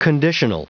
Prononciation du mot conditional en anglais (fichier audio)
Prononciation du mot : conditional